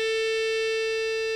Il faut commencer par enregistrer le son de l'instrument au diapason (🎧
vst_signal.flac), c'est-à-dire un La/A4 de 440 Hz selon l'accordage traditionnel. Nous obtenons un son visuellement simple, périodique et dont le spectre est déterminé dans la partie de sustain, c'est-à-dire au cœur de la note jouée après l'attack et avant le release.
Vue sinosoïdale de la flûte d'un accordéon